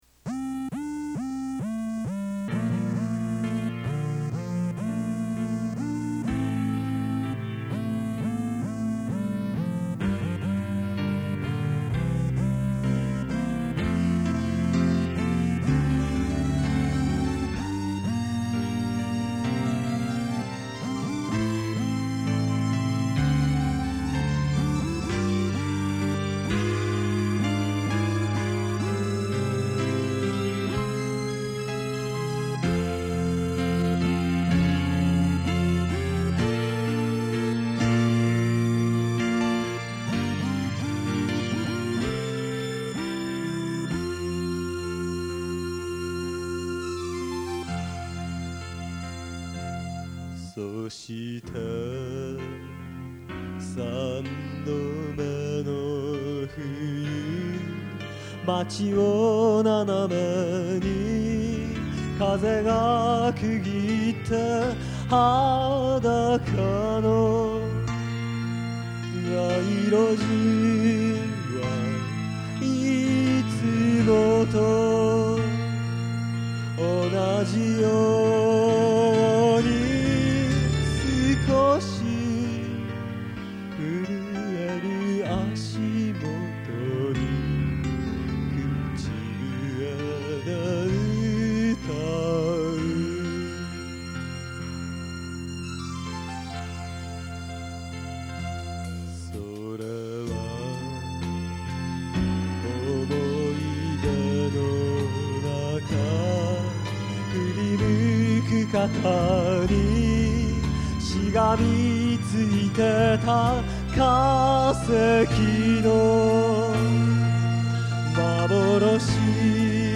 ここで紹介させていただきますのは，もう２０余年前，大学の頃，ひとり軽音楽部の部室で，多重録音で作ったオリジナル曲です。
全部，自分でやってますし，おまけに，おそろしいことに Vocal まで，私自身でやっております（なんせ，男性サイドの歌詞ばかりなもので･･）。
(一部で音が飛んでおります。御諒承ください。）